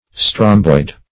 Meaning of stromboid. stromboid synonyms, pronunciation, spelling and more from Free Dictionary.
Search Result for " stromboid" : The Collaborative International Dictionary of English v.0.48: Stromboid \Strom"boid\, a. [Strombus + -oid.]